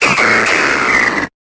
Cri de Quartermac dans Pokémon Épée et Bouclier.